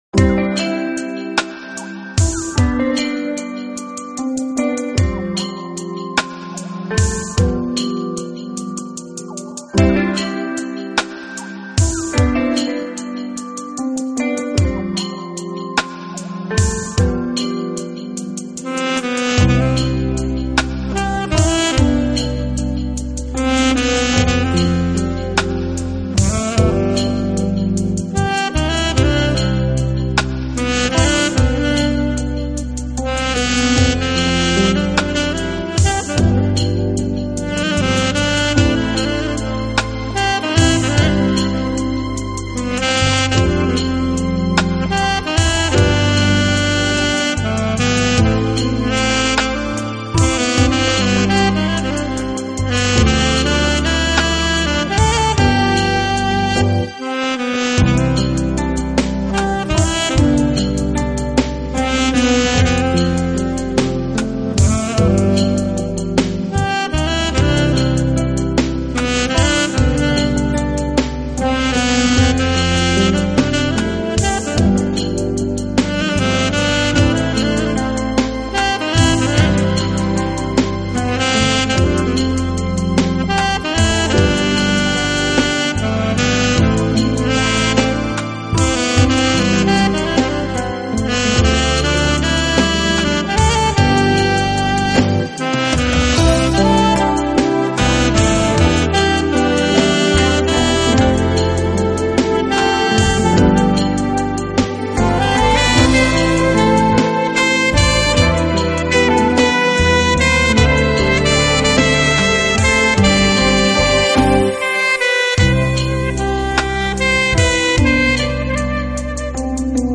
萨克斯专辑